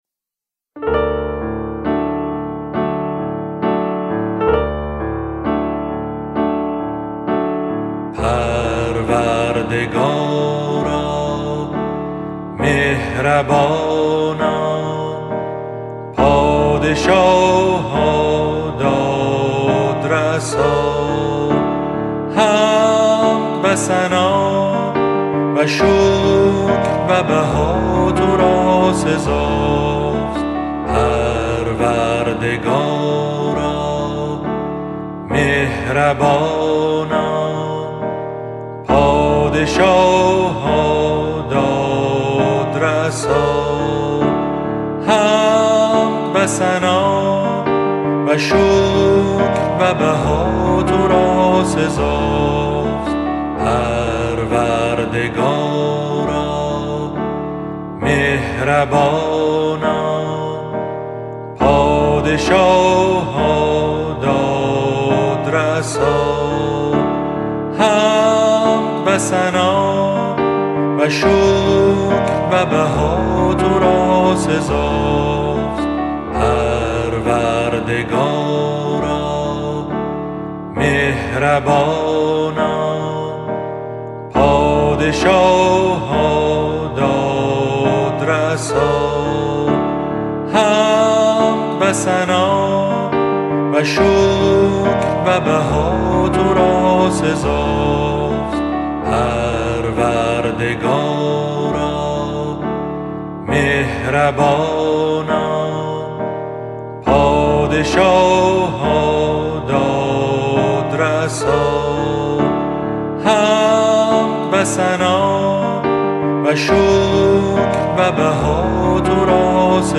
Download Track13.mp3 سایر دسته بندیها اذکار فارسی (آوازهای خوش جانان) 11098 reads Add new comment Your name Subject دیدگاه * More information about text formats What code is in the image?